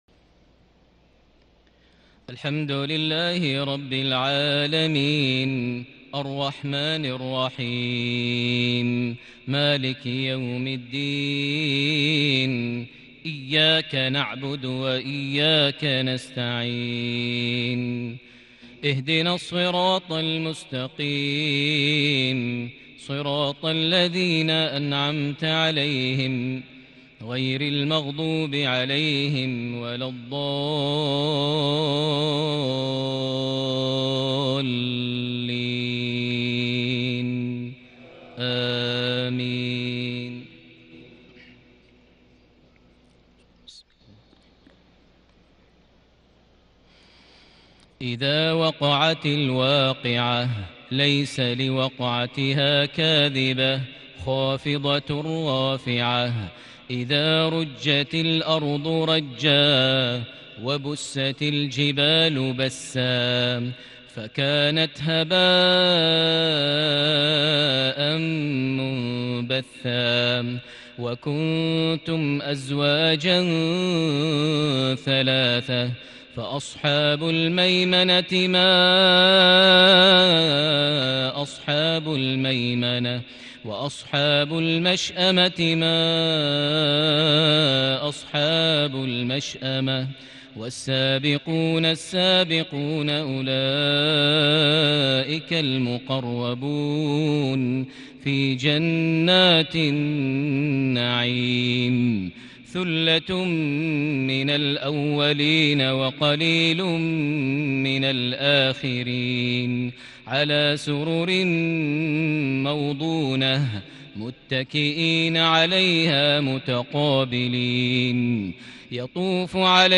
عشاء ١٣ صفر ١٤٤١هـ سورة الواقعة ١-٥٦ > 1441 هـ > الفروض - تلاوات ماهر المعيقلي